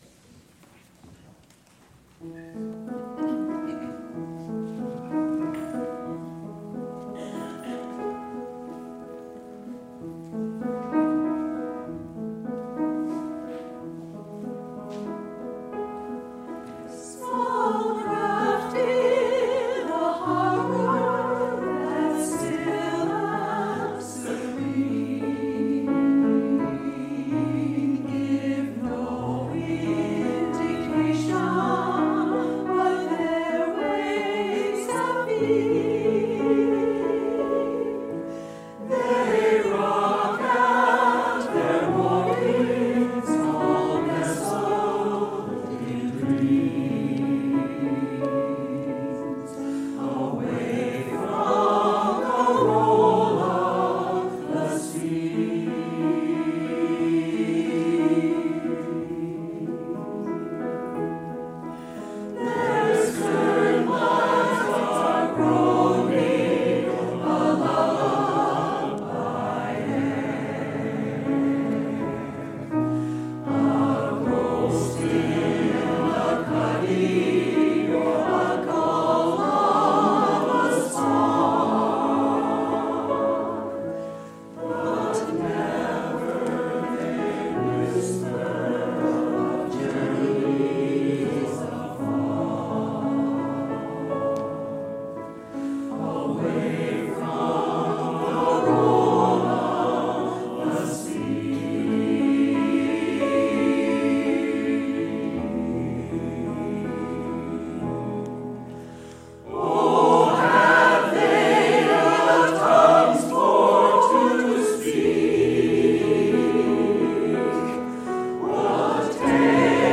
Past QUUF Choir Recordings